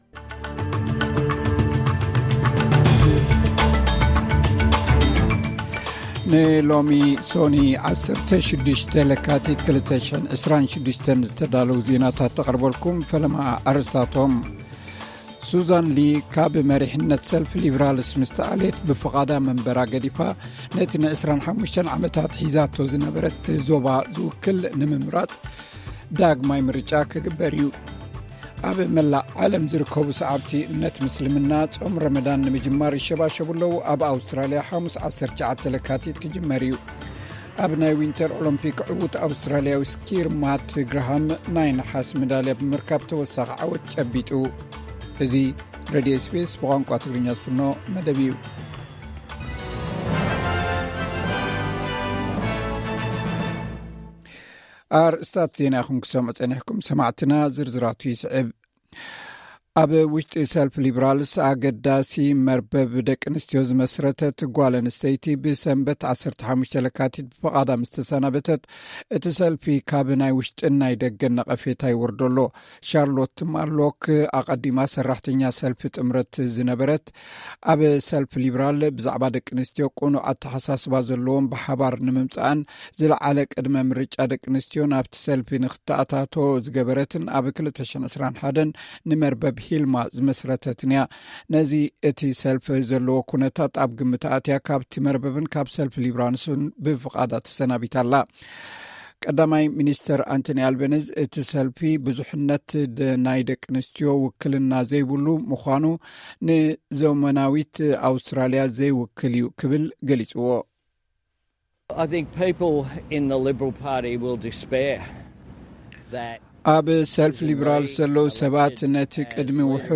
ዕለታዊ ዜና ኤስ ቢ ኤስ ትግርኛ (16 ለካቲት 2026)